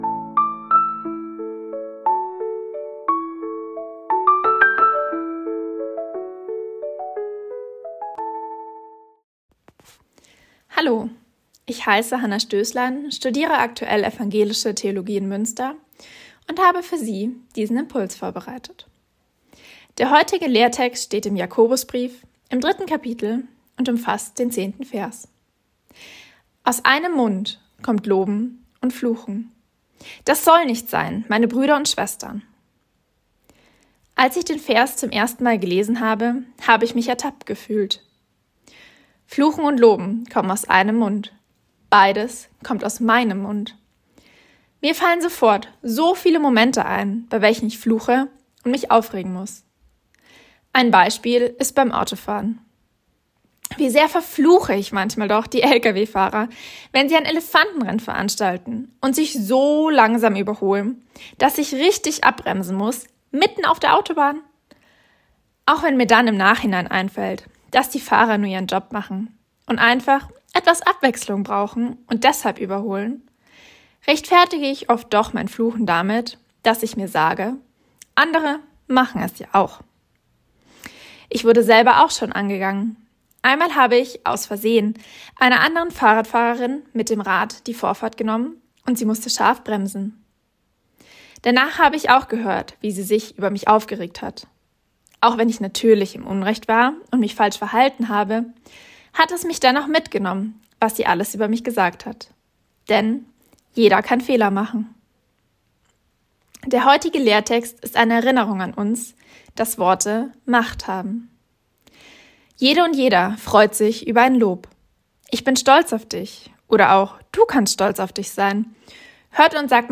Losungsandacht für Freitag, 08.08.2025 – Prot.